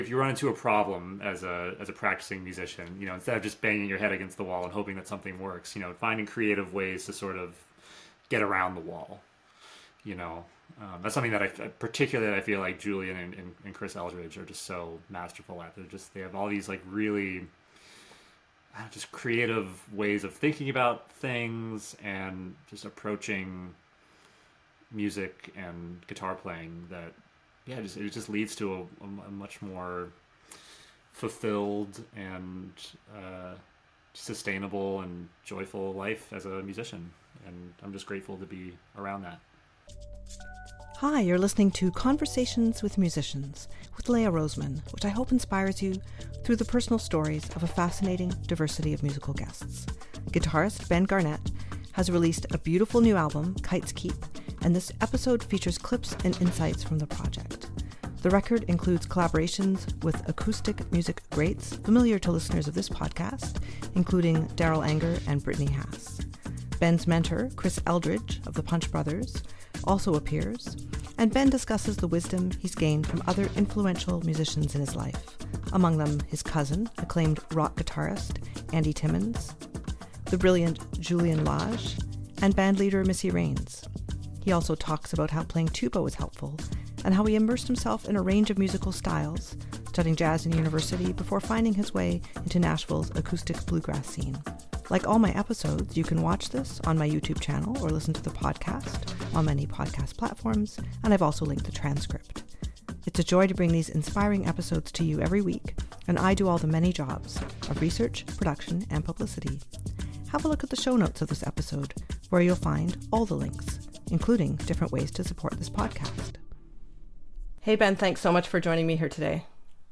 There’s a fascinating variety to a life in music; this series features wonderful musicians worldwide with in-depth conversations and great music. Many episodes feature guests playing music spontaneously as part of the episode or sharing performances and albums.